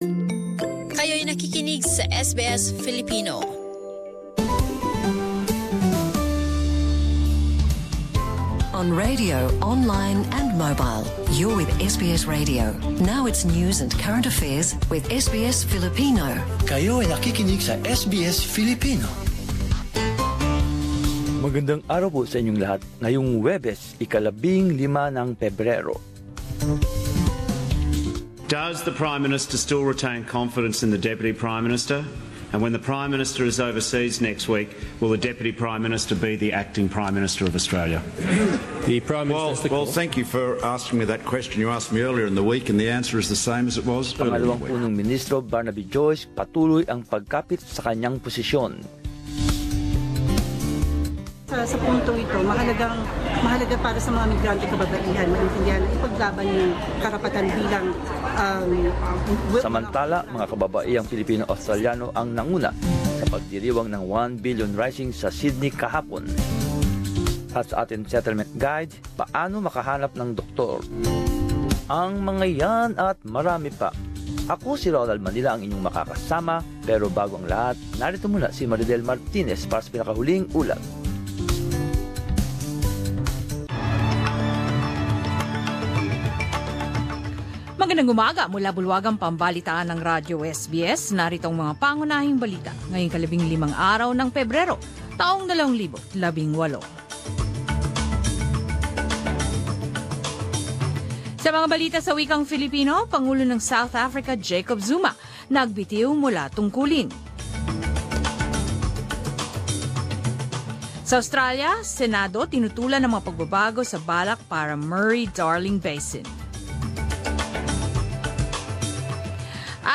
10 am News Bulletin